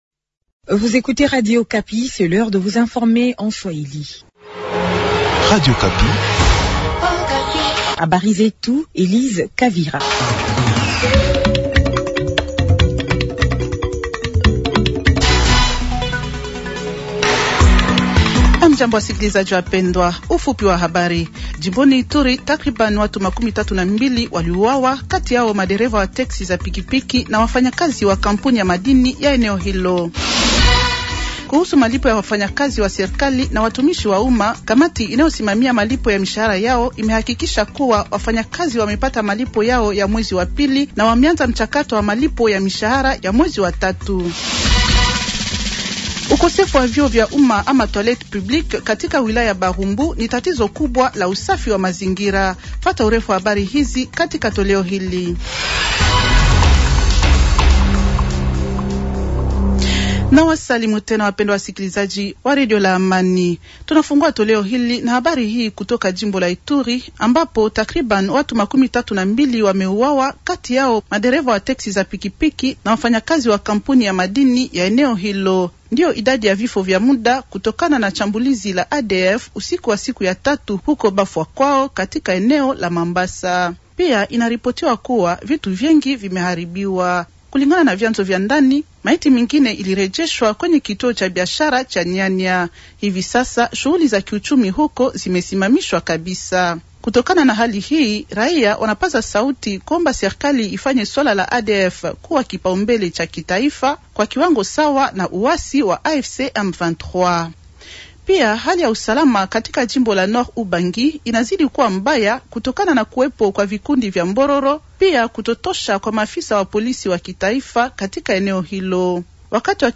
Journal swahili de vendredi soir 030426